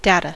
data2: Wikimedia Commons US English Pronunciations
En-us-data2.WAV